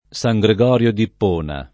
San Gregorio [ S a j g re g0 r L o ] top.